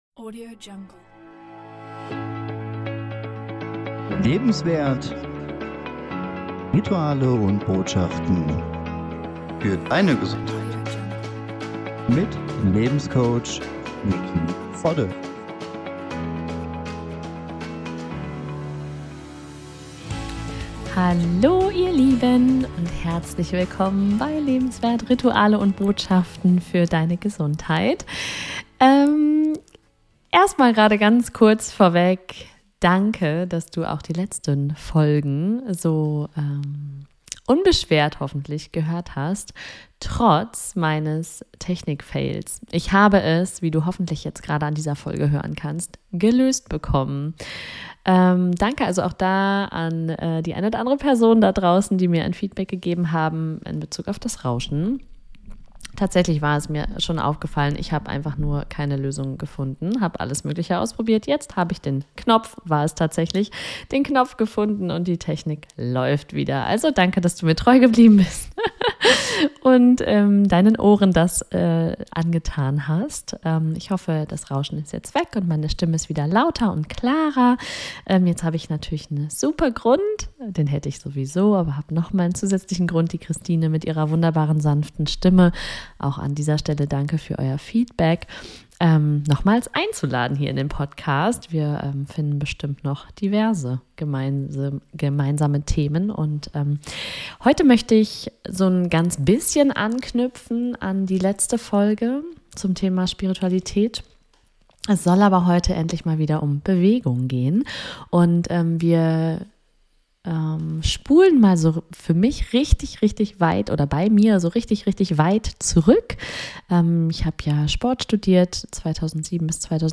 Als kleine Inspiration für Dich, wie eine Achtsamkeitsübung über Körperwahrnehmung und sanfte Bewegung aussehen kann und sich anfühlt, leite ich in dieser Folge die "Beckenuhr" von Moshé Feldenkrais für Dich an.